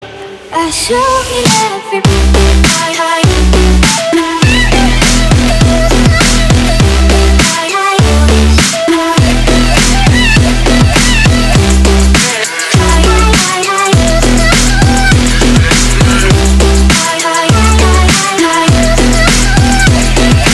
• Качество: 315, Stereo
громкие
remix
dance
Electronic
club
забавный голос